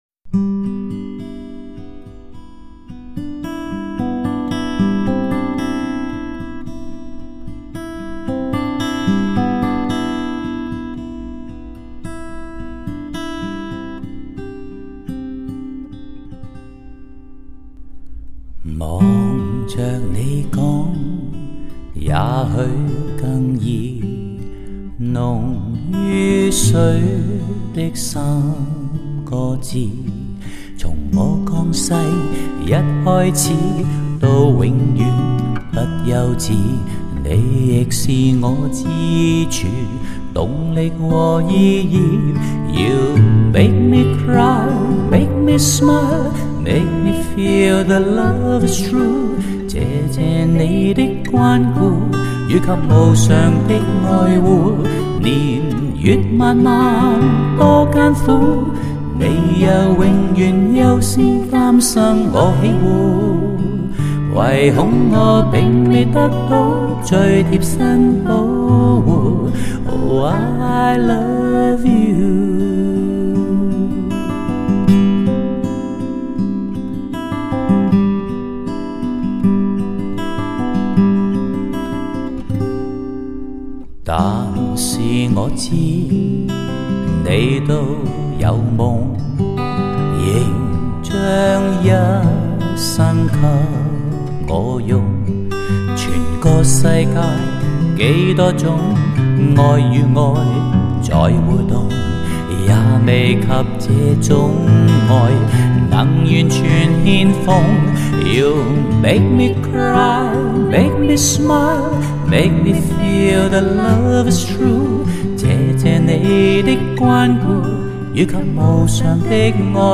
唱片类型：华语流行